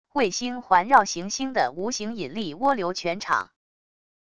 卫星环绕行星的无形引力涡流全场wav音频